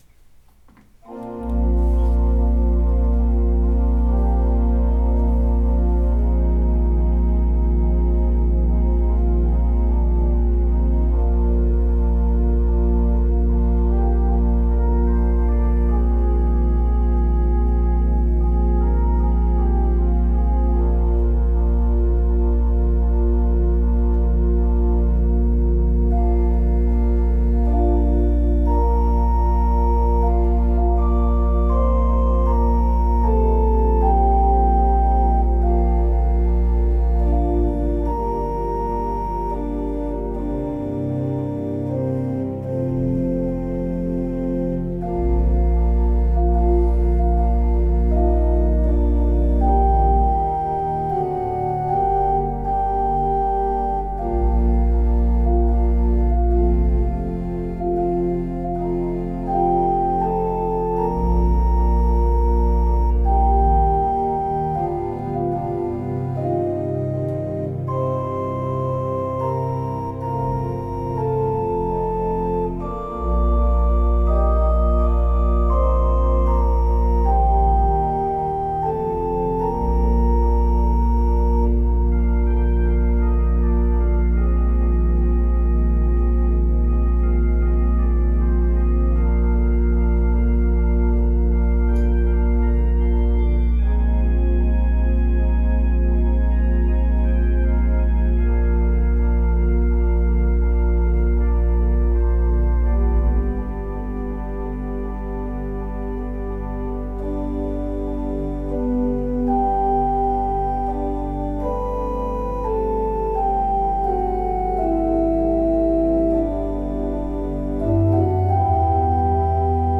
I wrote this piece in honor of my grandchildren. It’s supposed to be like a lullaby.